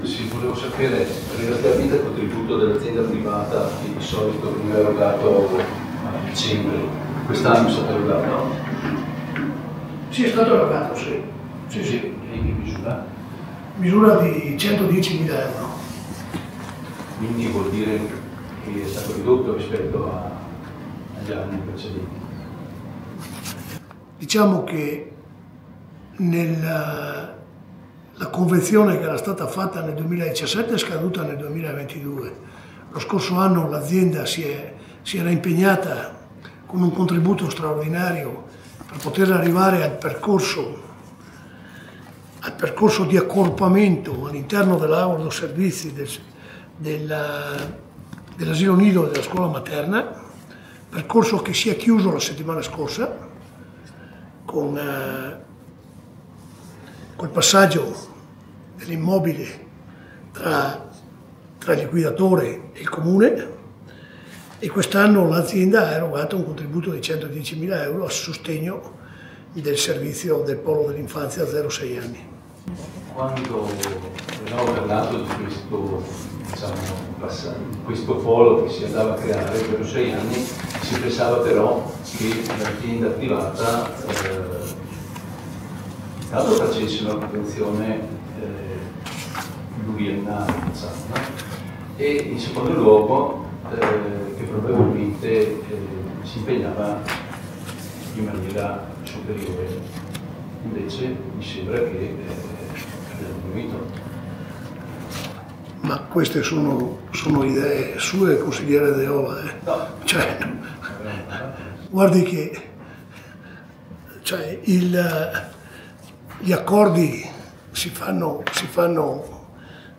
Ad annunciarlo il sindaco di Agordo Roberto Chissalè nel consiglio comunale di fine 2024
DAL CONSIGLIO COMUNALE DI AGORDO